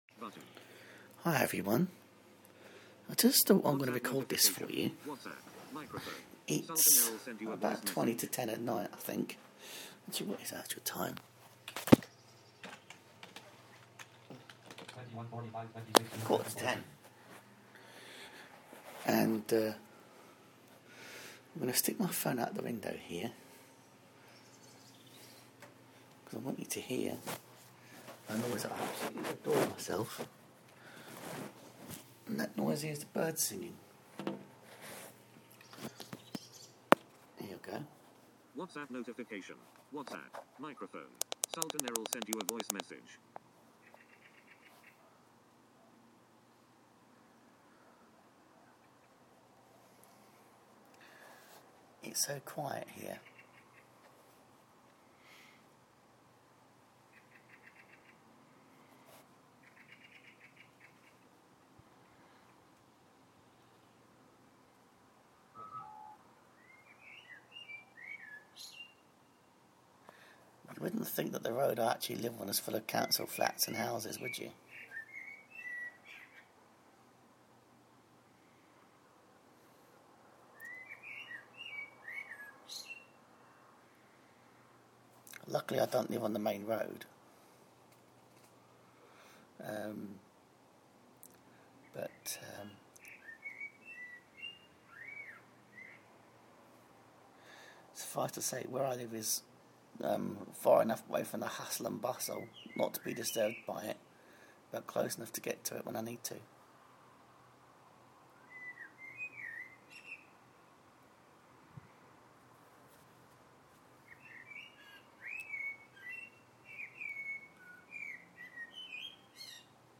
Bird Song at night